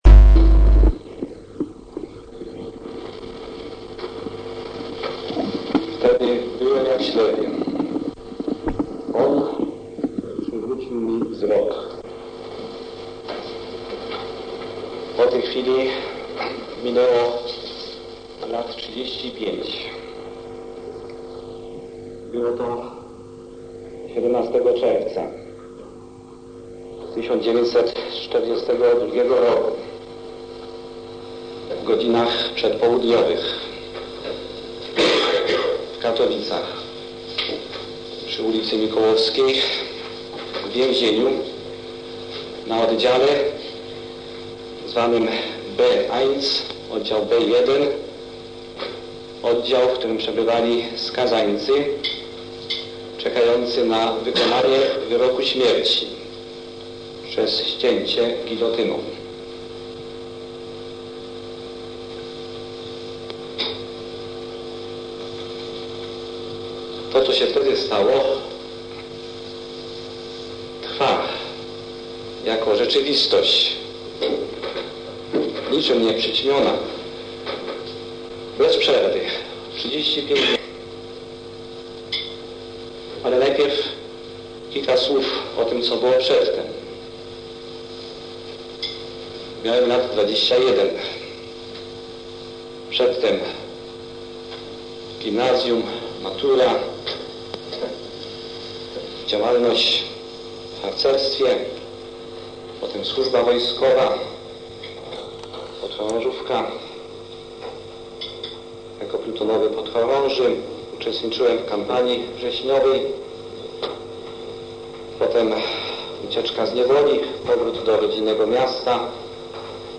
Prof. Blachnicki Świadectwo audio - Krościenko REKOLEKCJE DLA NARZECZONYCH I MAŁŻEŃSTW ZŁOTE RECEPTY 1.